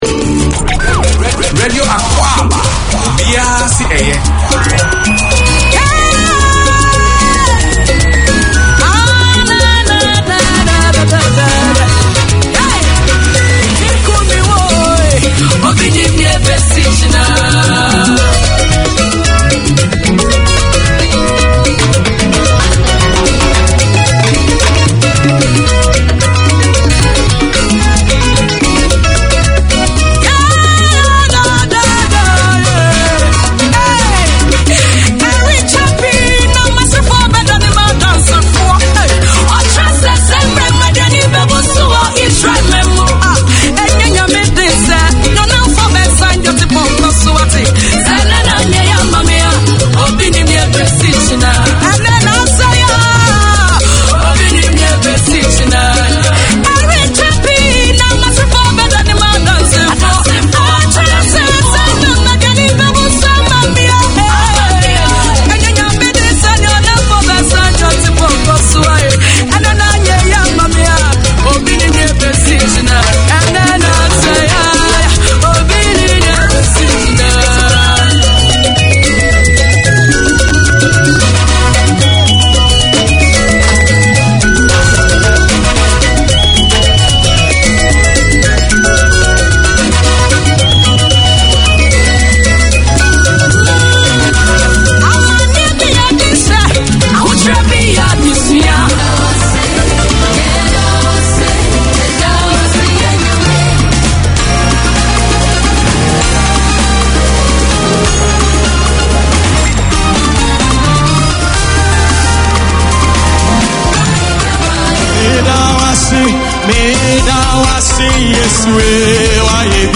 Radio made by over 100 Aucklanders addressing the diverse cultures and interests in 35 languages.
Radio Akwaaba is a programme targeted to the Ghanaian Community and all those who are interested in Ghana or African culture. It brings news, current affairs and sports reporting from Ghana along with music and entertainment.